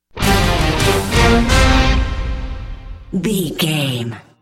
Ionian/Major
D
horns
drums
electric guitar
synthesiser
orchestral hybrid
dubstep
aggressive
energetic
intense
strings
bass
synth effects
wobbles
driving drum beat
epic